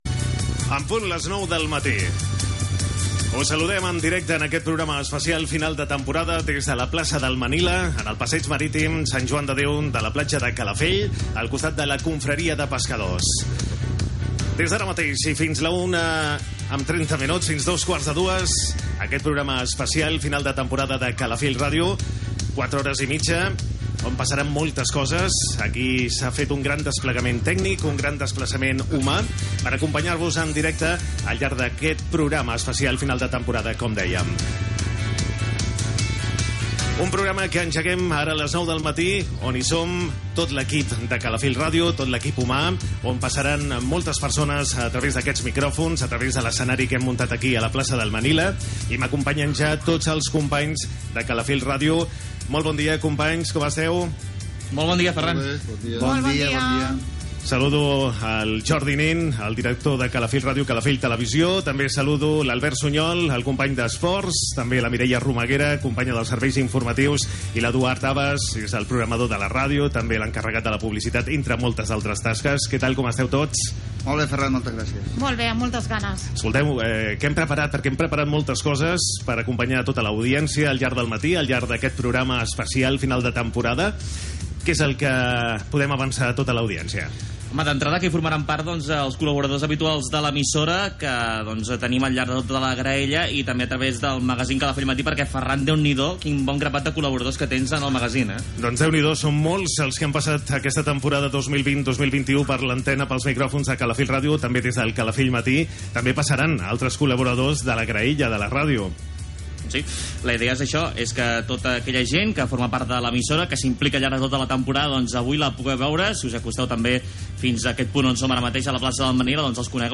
Programa fet des de la Plaça del Manila de Calafell. Hora, presentació de l'especial final de temporada, amb la participació de tots els presentadors i col·laboradors de l'emissora.
Entreteniment